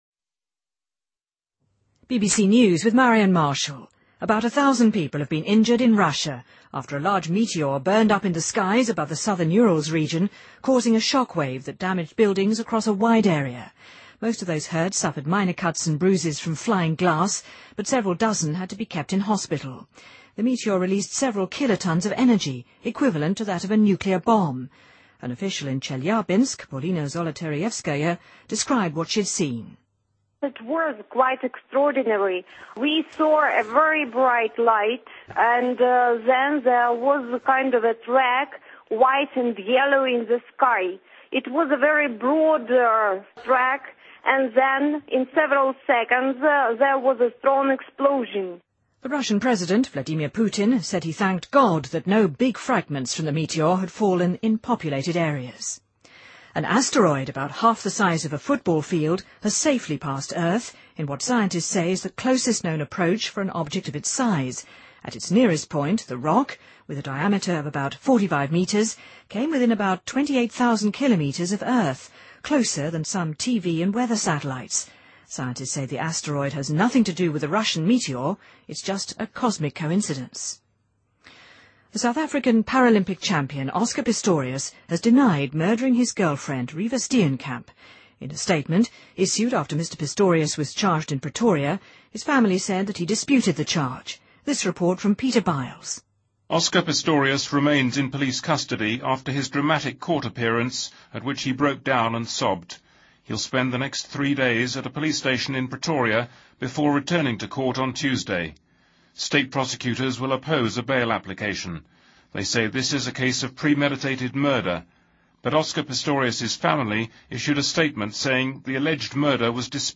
BBC news,俄罗斯天降陨石雨造成1000多人受伤